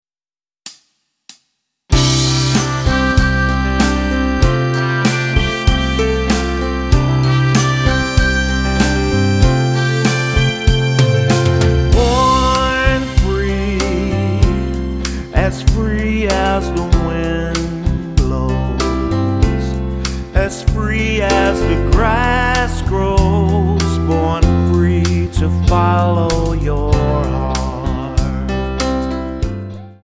Tonart:C-Eb Multifile (kein Sofortdownload.
Die besten Playbacks Instrumentals und Karaoke Versionen .